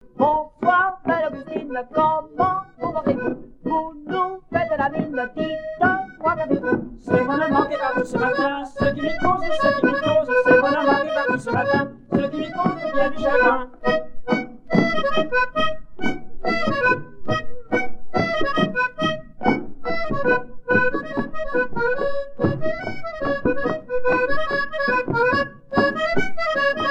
Polka piquée
Couplets à danser
danse : polka piquée